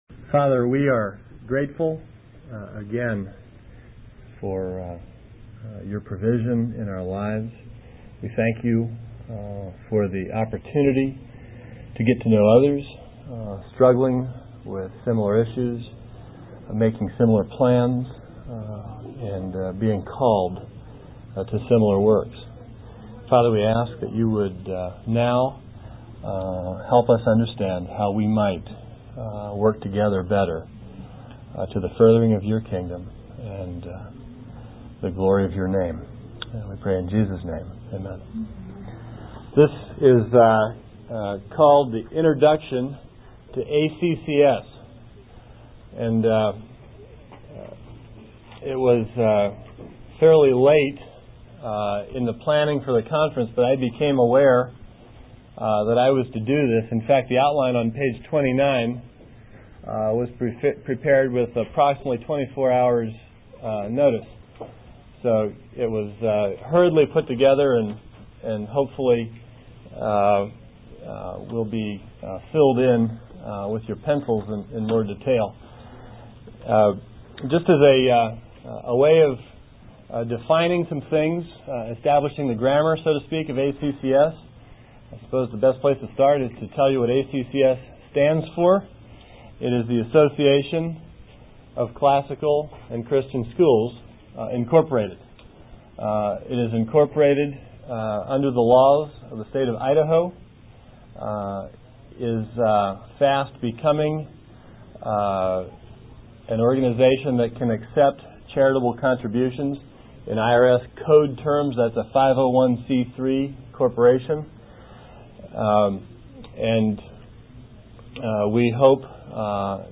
1995 Practicum Talk | 1:02:29 | All Grade Levels
Additional Materials The Association of Classical & Christian Schools presents Repairing the Ruins, the ACCS annual conference, copyright ACCS.